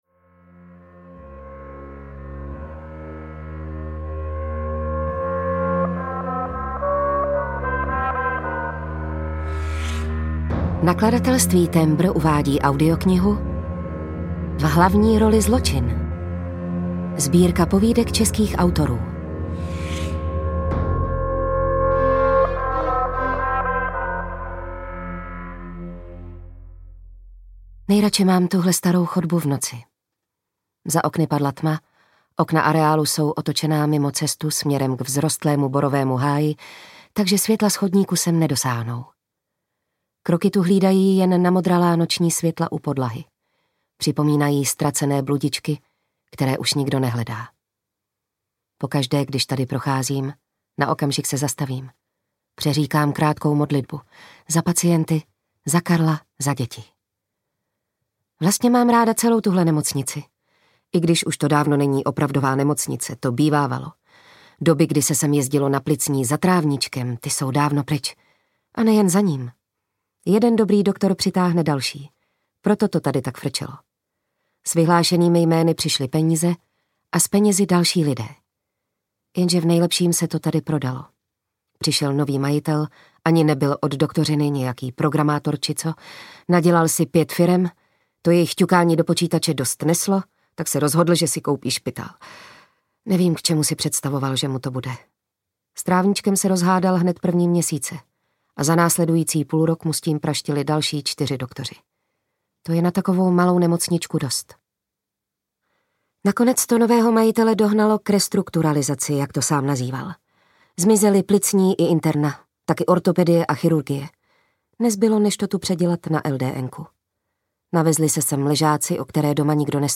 Audio knihaV hlavní roli zločin
Ukázka z knihy